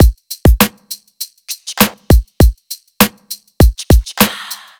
FK100BEAT1-L.wav